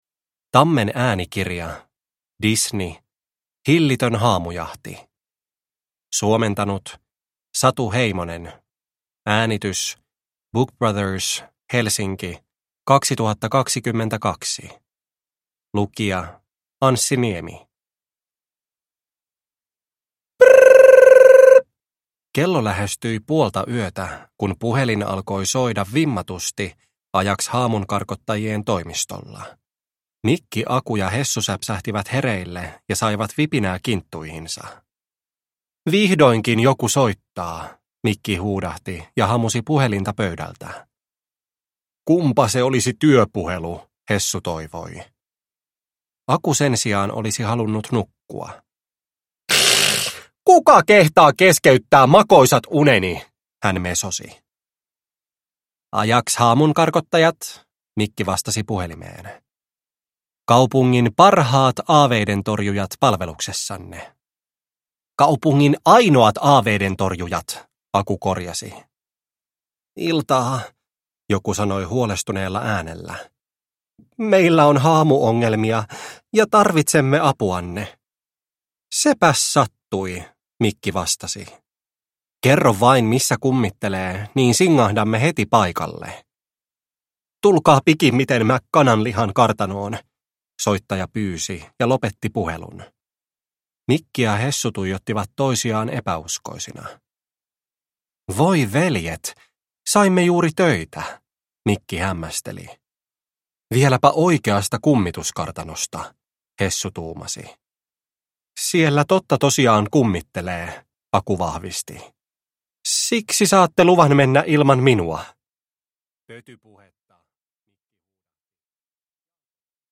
Disney. Hillitön haamujahti – Ljudbok – Laddas ner